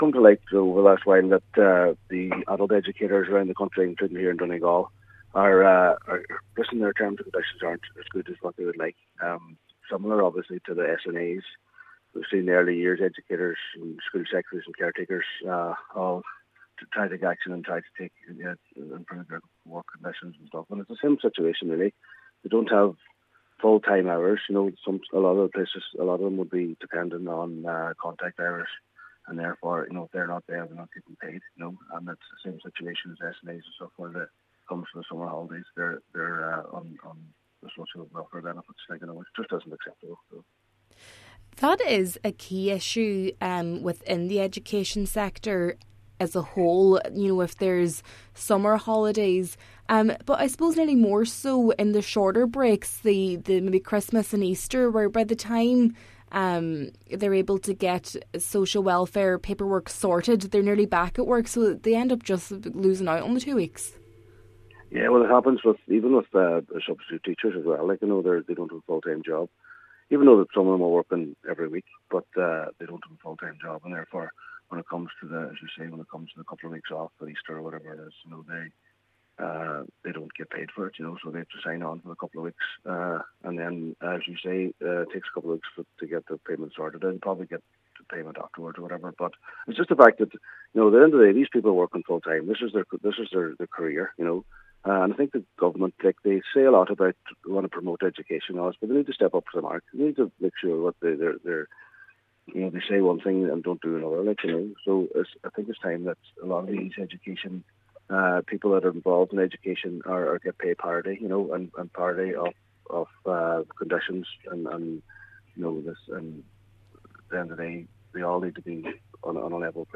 Cllr Jimmy Brogan says the Government needs to show it values education, rather than just saying it does: